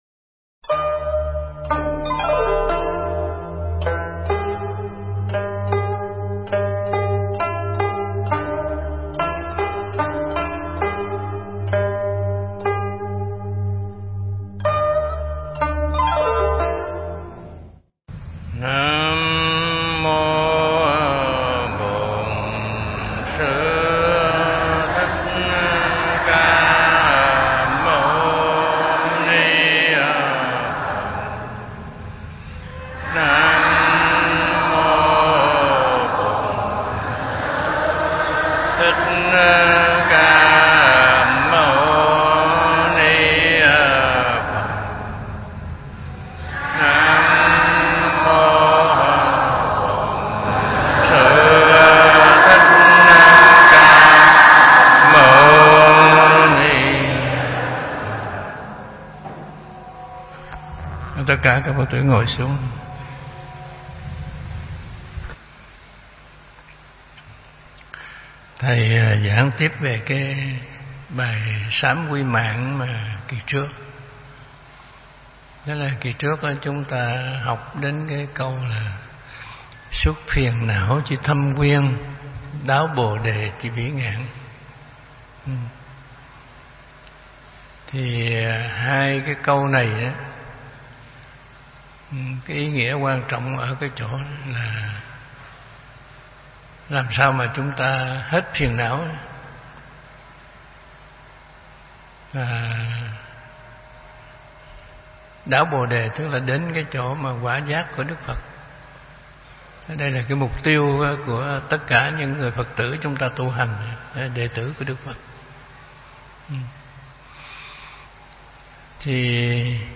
Nghe Mp3 thuyết pháp Sám Quy Mạng Phần 3 – Thích Trí Quảng
Mp3 Thuyết Pháp Sám Quy Mạng Phần 3 – Hòa Thượng Thích Trí Quảng giảng tại chùa Huê Nghiêm (quận 2, HCM) ngày 8 tháng 4 năm 2017, (ngày 12 tháng 3 năm Đinh Dậu)